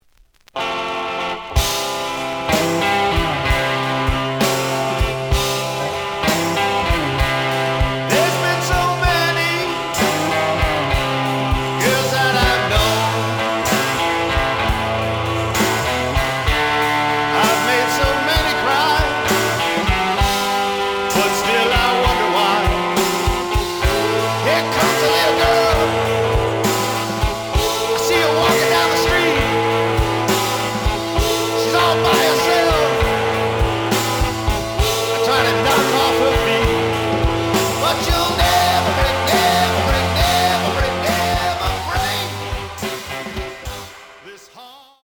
The audio sample is recorded from the actual item.
●Genre: Rock / Pop
Slight edge warp.